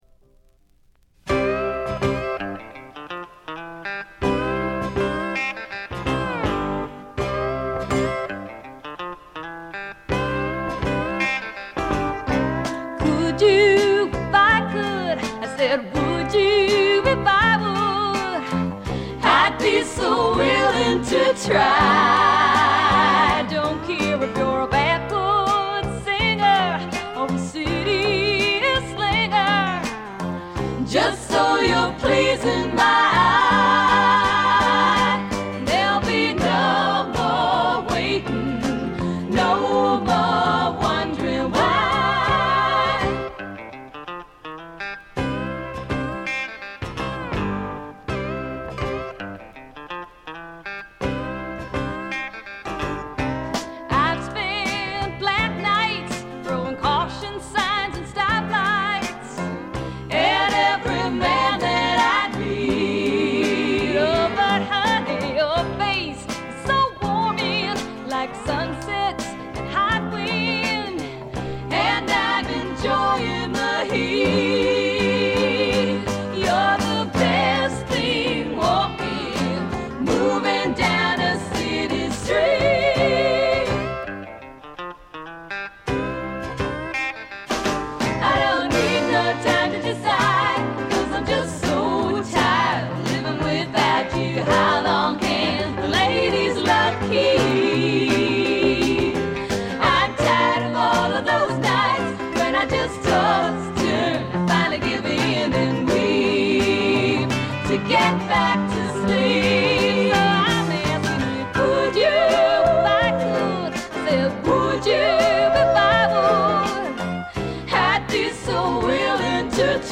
3人娘のヴォーカル・ユニット
フォーク・ロックとしても、ソフト・サイケとしても、ポップ･ヴォーカルとしても、極めてクオリティの高い内容です。
試聴曲は現品からの取り込み音源です。